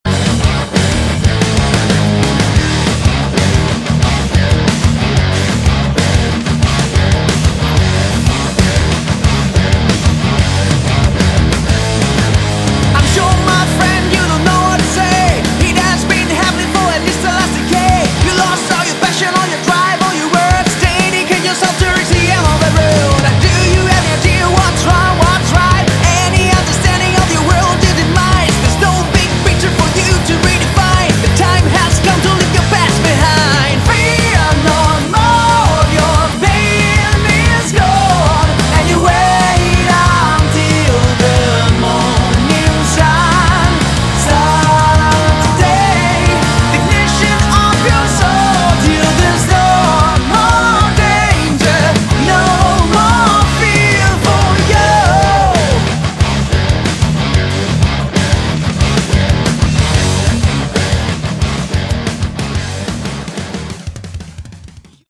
Category: Modern Hard Rock
vocals, guitars
bass
drums